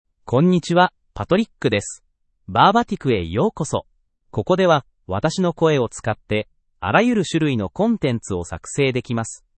MaleJapanese (Japan)
PatrickMale Japanese AI voice
Patrick is a male AI voice for Japanese (Japan).
Voice sample
Listen to Patrick's male Japanese voice.
Patrick delivers clear pronunciation with authentic Japan Japanese intonation, making your content sound professionally produced.